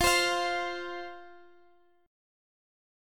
F5 chord